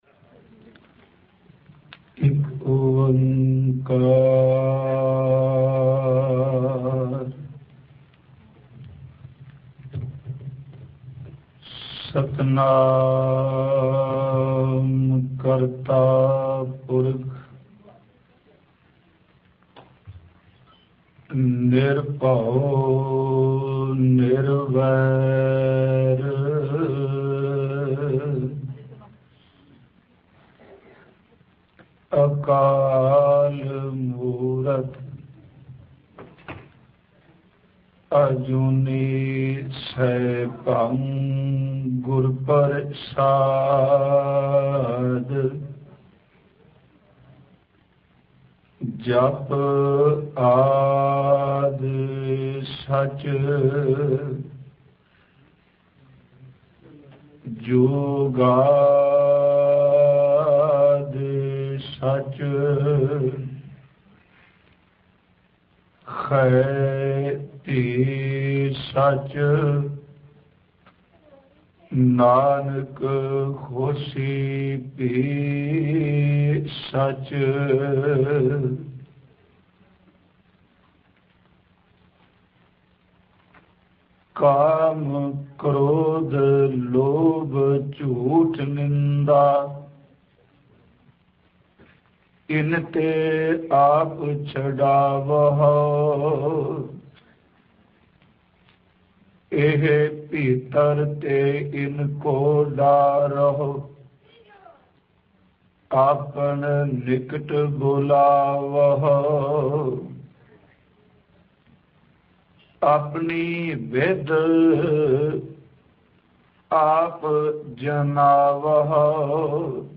Katha
Album:AMRIT Genre: Gurmat Vichar